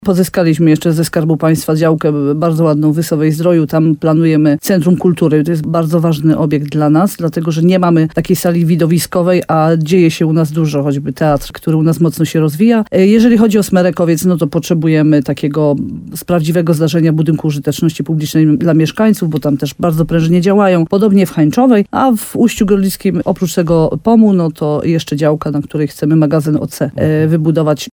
– Różne pomysły leżą na stole – powiedziała w programie Słowo za Słowo w radiu RDN Nowy Sącz wójt gminy Uście Gorlickie, Ewa Garbowska-Góra.